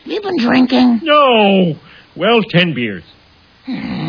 Tags: Top 10 Sound Clips Sound Clips Movie sound clips Monty Python Die Hard